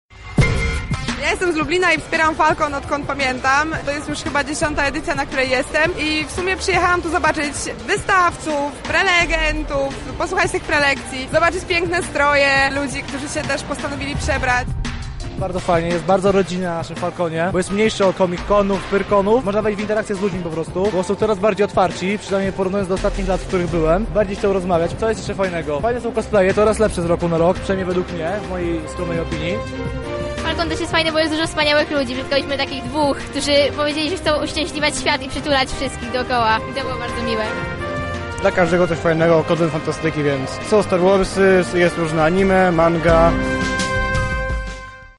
Falkon zgromadził fanów fantastyki
Uczestnicy opowiedzieli o swoich wrażaniach.